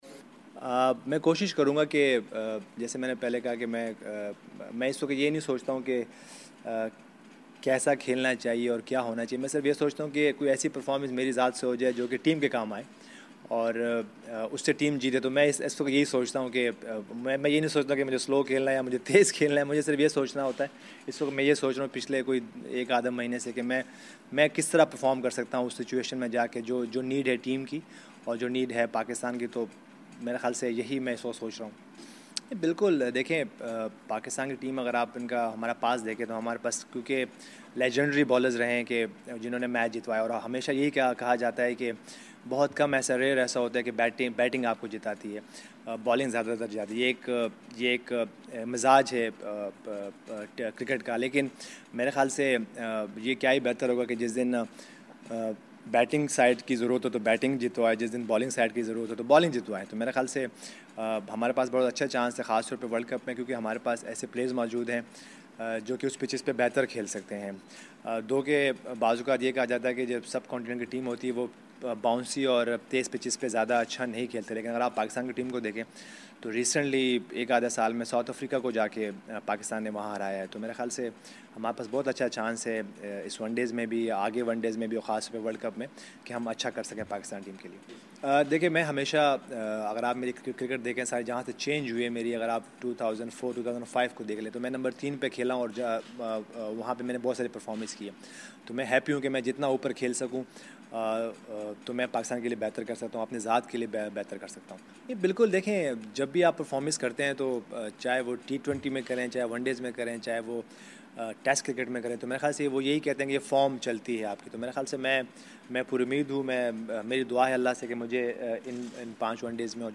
Younus Khan, the Pakistan batsman, media conference, 7 December (Urdu)